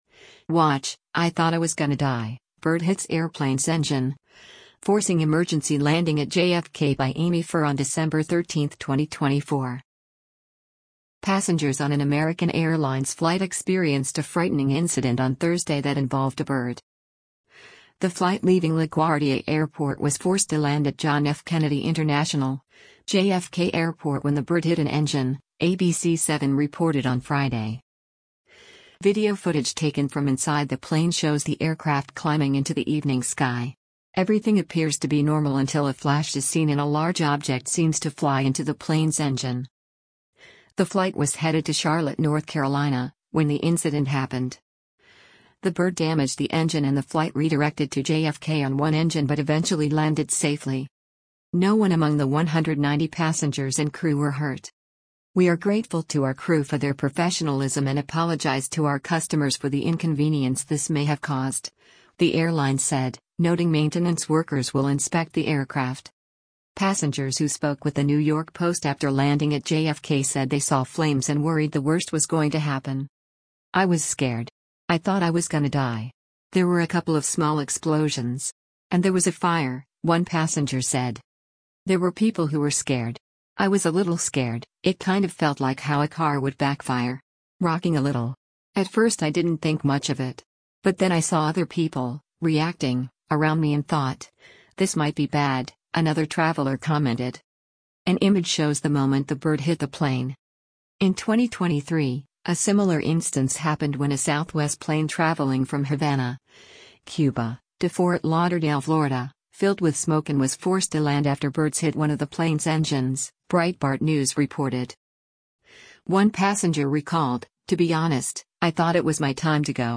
Video footage taken from inside the plane shows the aircraft climbing into the evening sky.